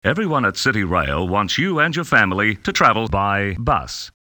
This reminds me of an old doctored rail announcement (from Cityrail in NSW) in which the voice implores people to travel